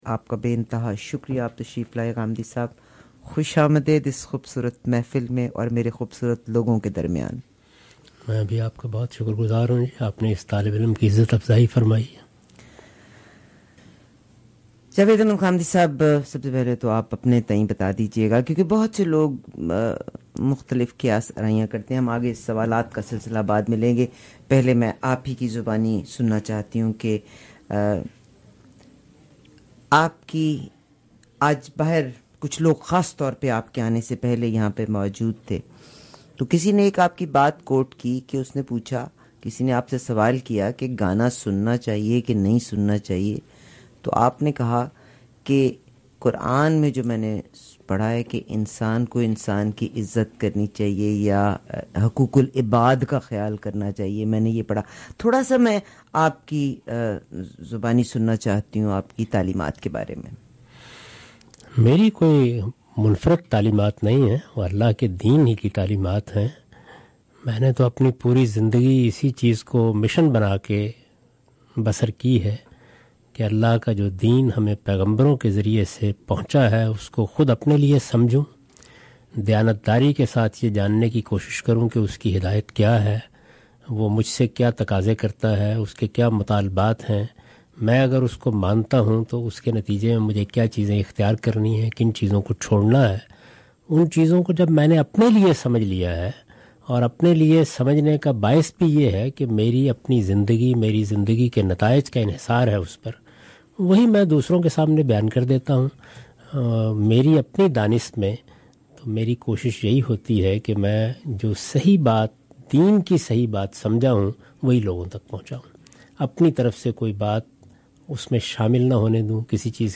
Guest: Javed Ahmad Ghamidi